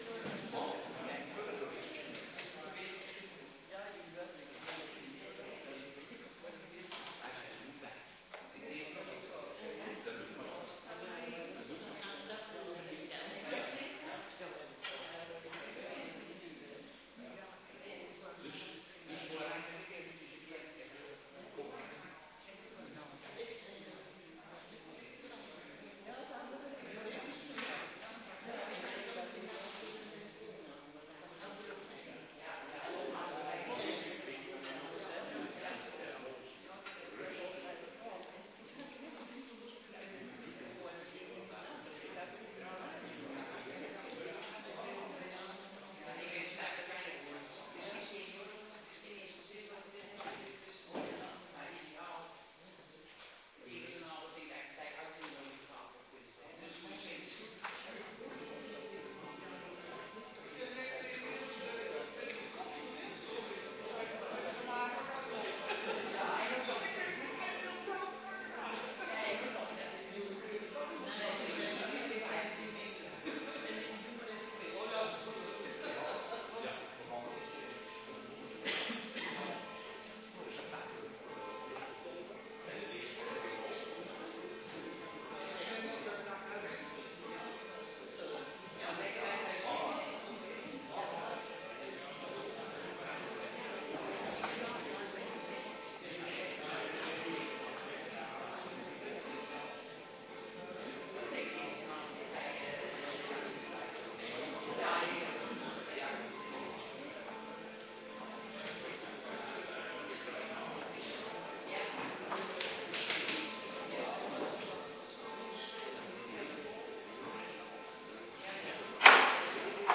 Kerkdiensten